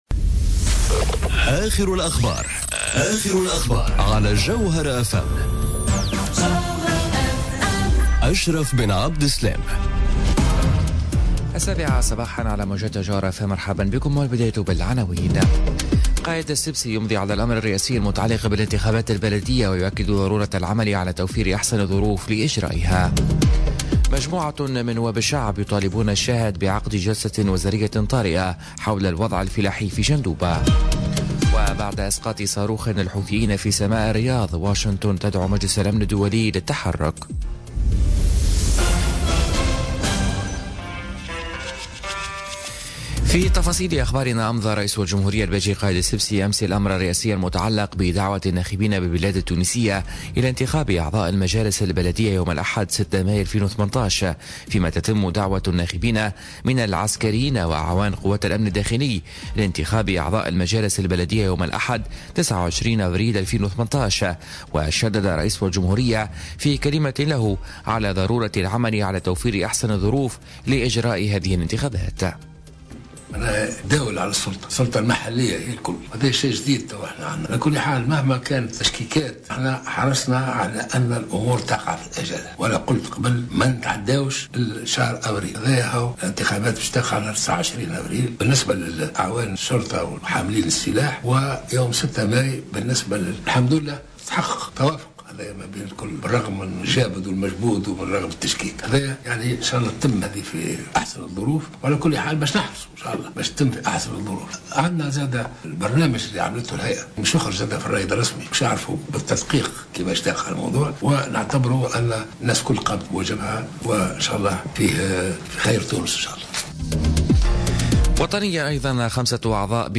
نشرة أخبار السابعة صباحا ليوم الإربعاء 20 ديسمبر 2017